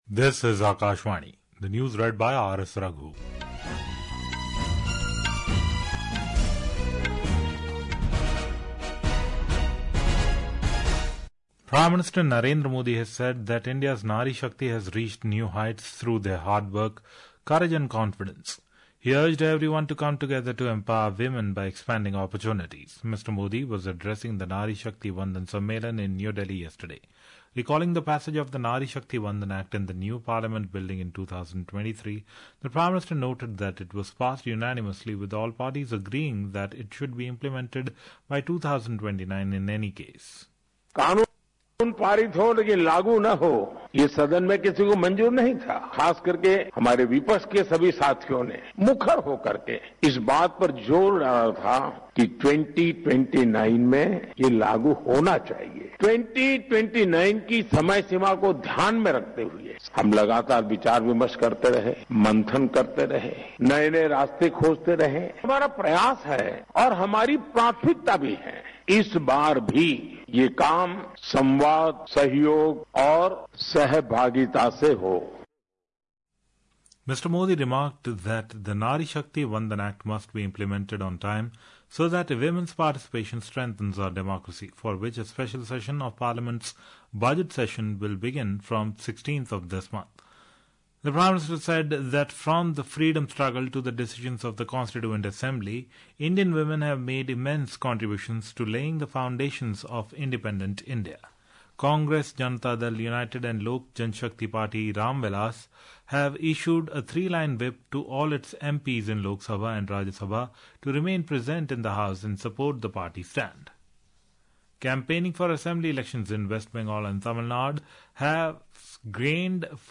રાષ્ટ્રીય બુલેટિન
Hourly News